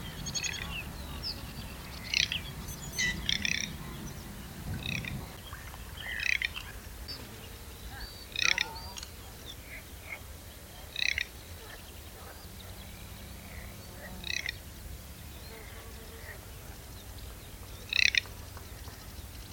zomertaling
🔭 Wetenschappelijk: Anas querquedula
zomertaling_zang.mp3